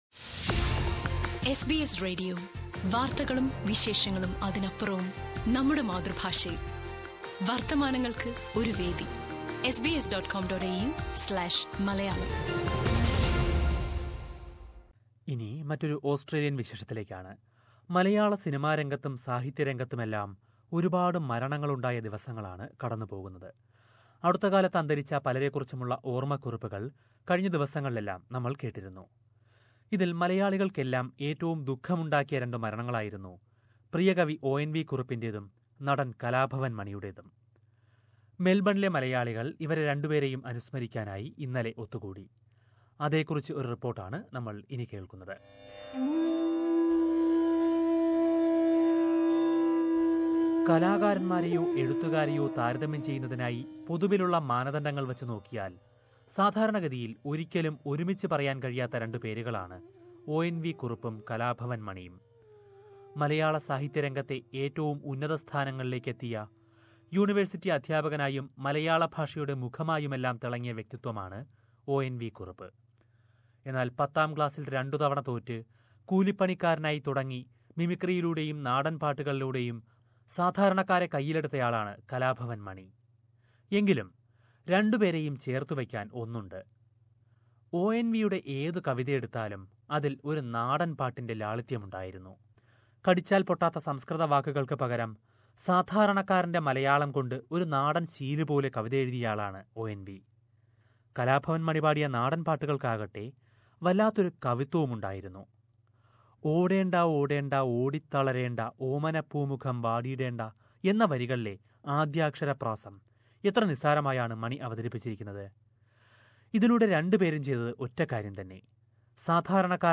ഇവർക്ക് ആദരാഞ്ജലികൾ അർപ്പിച്ചു കൊണ്ട് മെൽബണിലെ മലയാളം കൾച്ചറൽ സ്റ്റഡി സെൻടറും, ചാലക്കുടി അസ്സോസിയേഷനും ചേർന്ന് അനുസ്മരണ യോഗം സംഘടിപ്പിച്ചു. ഒ എൻ വി യുടെ കവിതകളും, മണിയുടെ നാടൻ പാട്ടുകളുമൊക്കെ നിറഞ്ഞ ആ പരിപടിയുടെ റിപ്പോർട്ട് കേൾക്കാം, മുകളിലെ പ്ലേയറിൽ നിന്ന്..